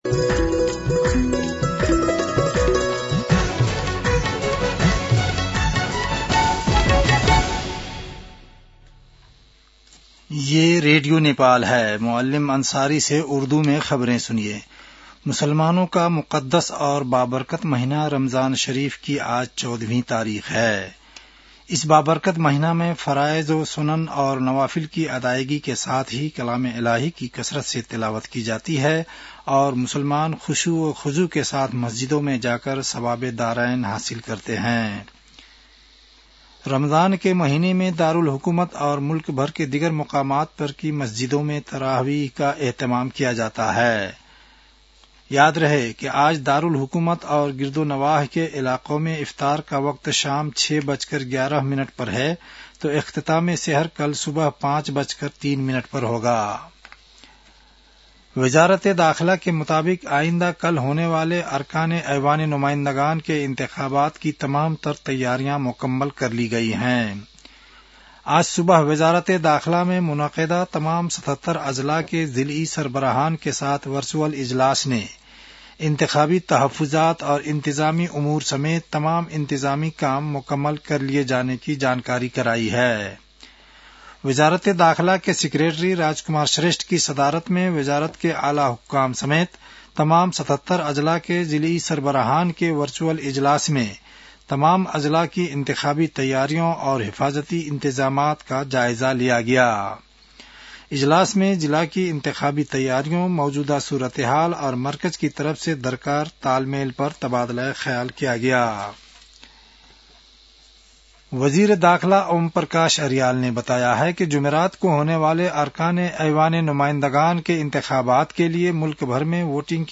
उर्दु भाषामा समाचार : २० फागुन , २०८२